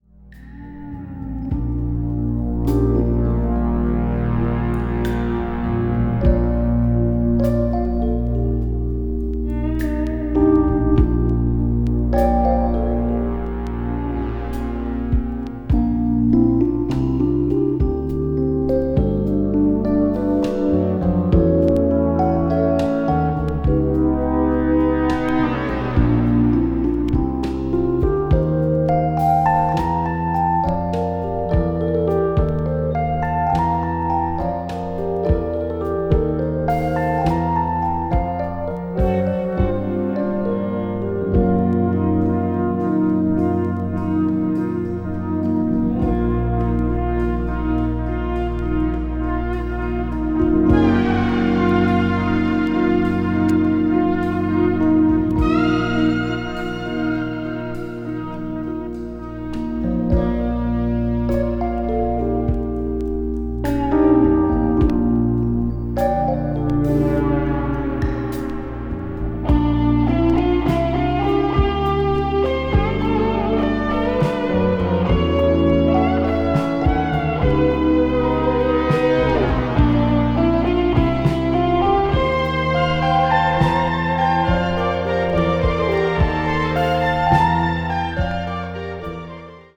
ピアノやシンセサイザーが静かに広がって行くメディテーティヴな世界